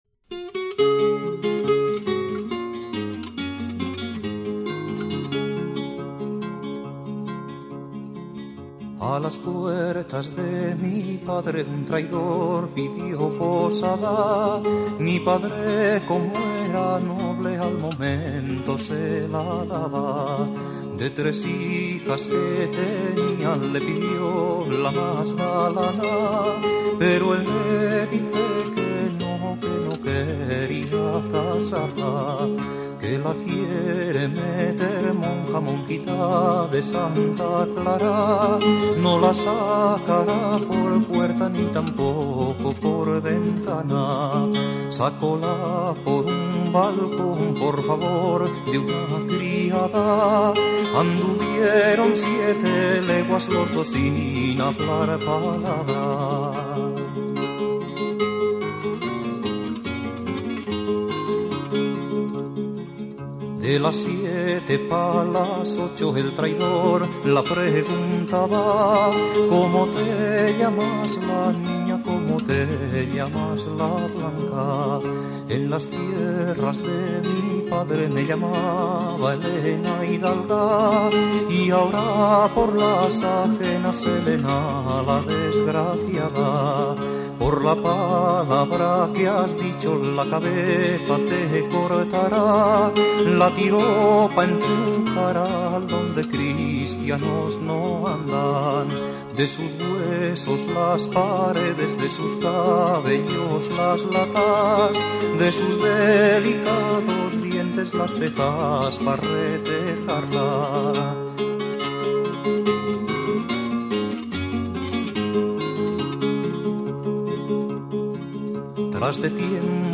Guitarras y voz